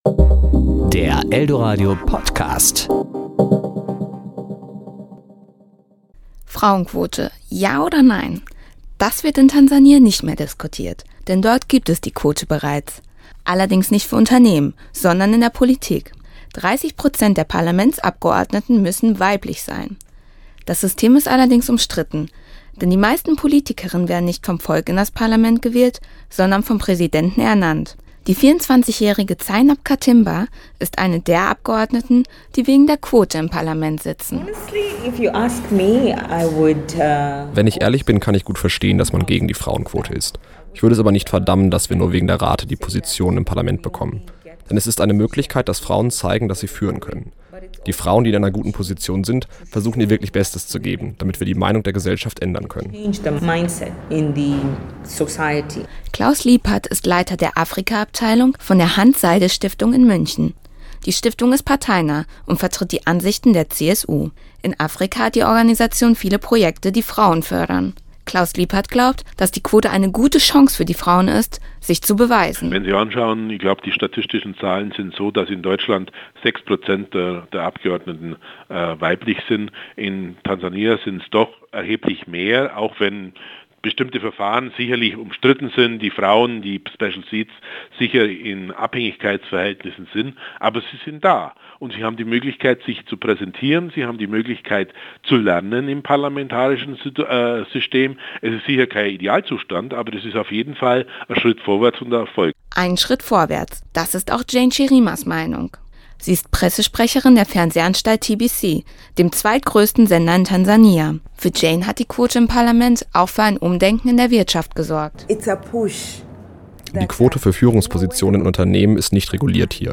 Serie: Beiträge  Ressort: Wort  Sendung: Toaster